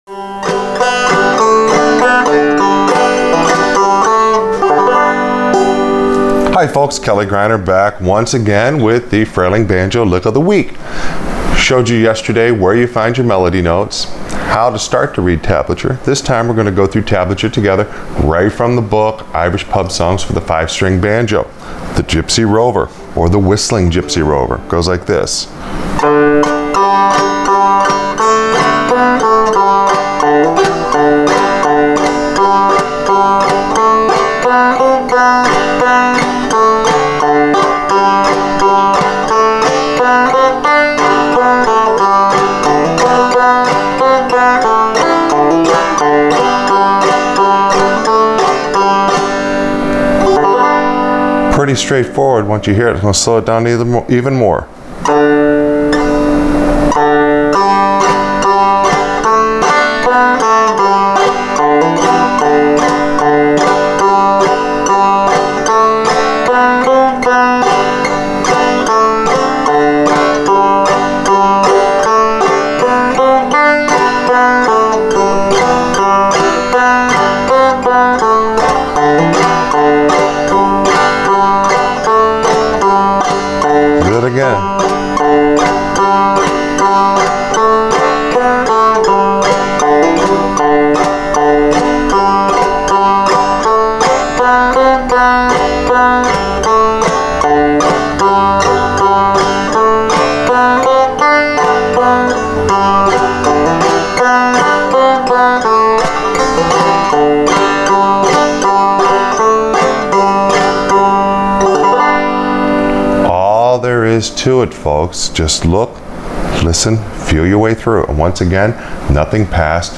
Frailing Banjo Lick Of The Week – Play Along With Tablature